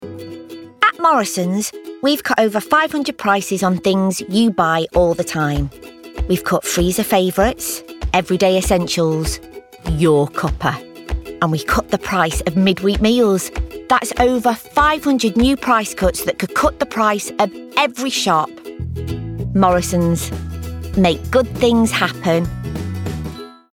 ***NEW ARTIST*** | 50s | Warm, Quirky & Natural
Voice reel